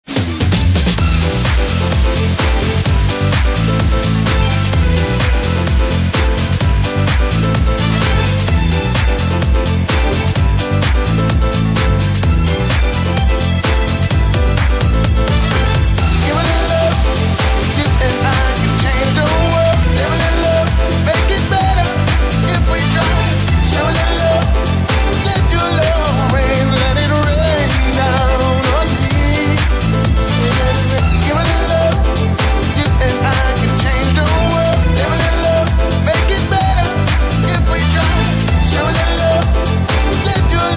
Электронная
Клубный микс